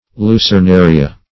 Search Result for " lucernaria" : The Collaborative International Dictionary of English v.0.48: Lucernaria \Lu`cer*na"ri*a\, n. [NL., fr. L. lucerna a lamp.]